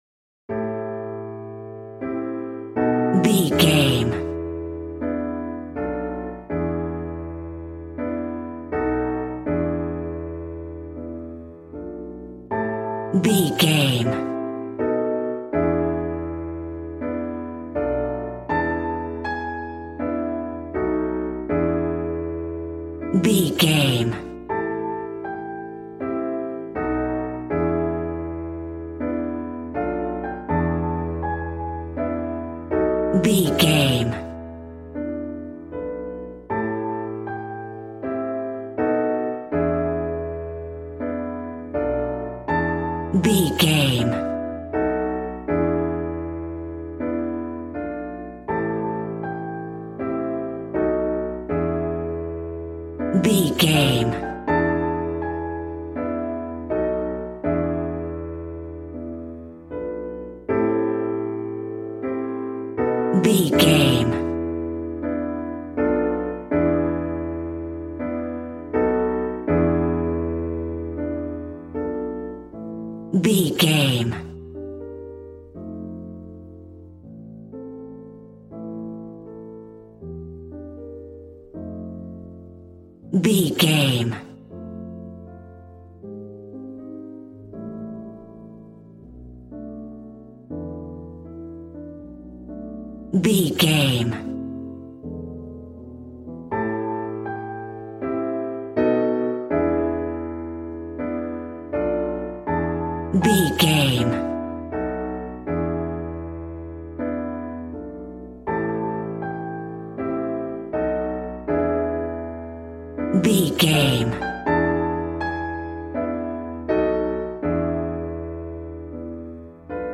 Ionian/Major
smooth
piano
drums